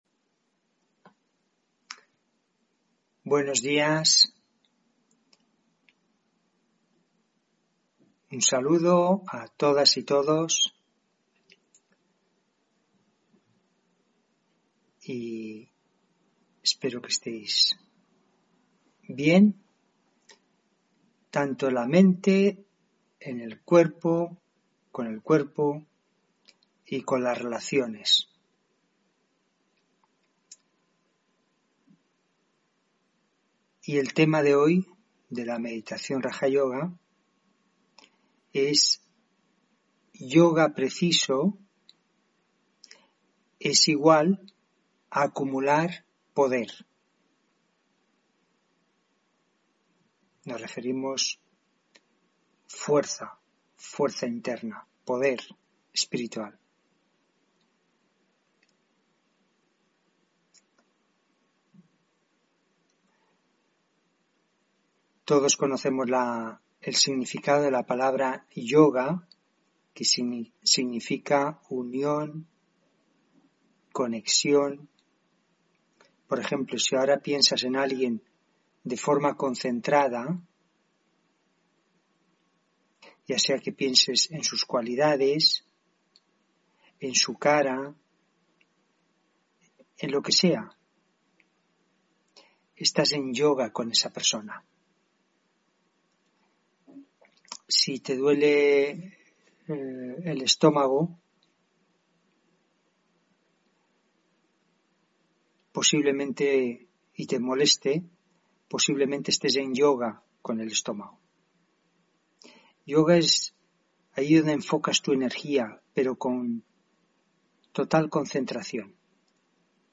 Meditación de la mañana: Yoga preciso=Acumular poder interior